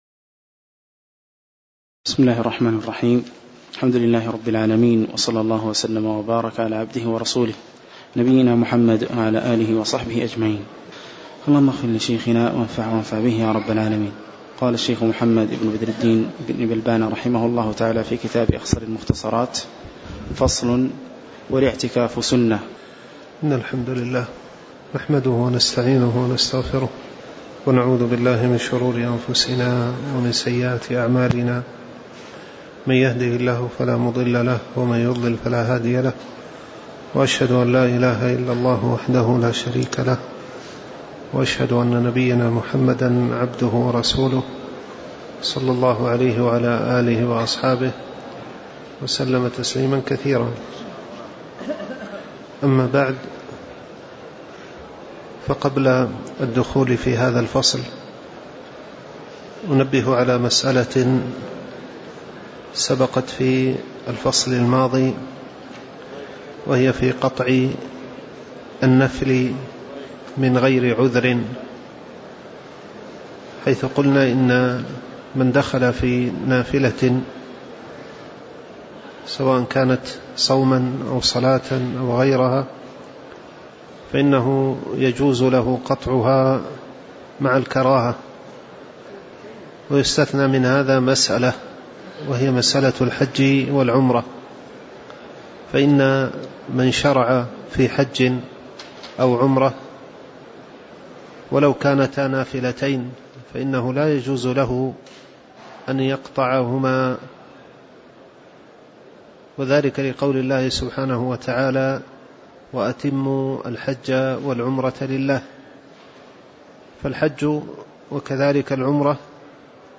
تاريخ النشر ٢٧ شعبان ١٤٣٩ هـ المكان: المسجد النبوي الشيخ